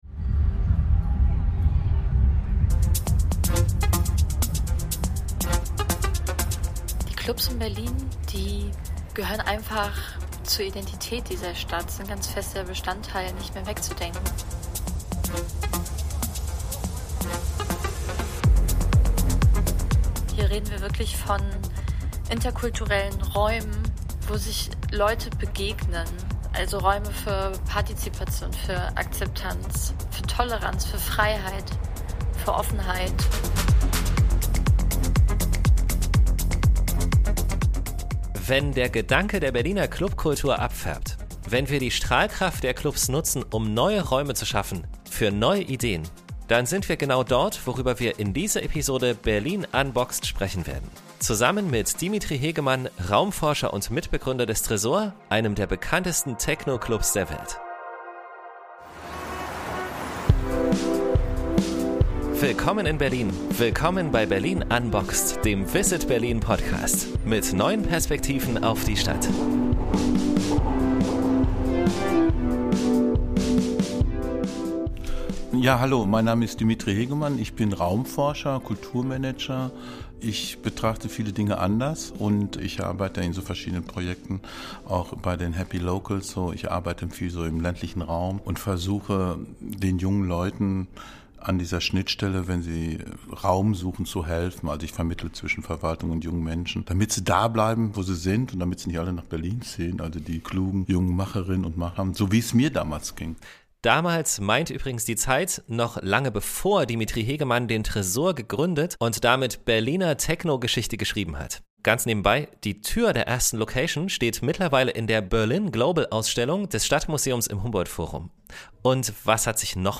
Wie wir die Strahlkraft der Clubs nutzen können, um neue Räume zu schaffen für neue Ideen, darum geht es in dieser Folge von Berlin Unboxed. Dazu haben wir Dimitri Hegemann, Raumforscher und Gründer des legendären Tresor Clubs, zum Gespräch gebeten. Gemeinsam mit ihm schauen wir über die Grenzen von Berlin hinaus und überlegen, wo auch mitten in der Stadt mit neuen Raumkonzepten spannende neue Freiräume entstehen könnten.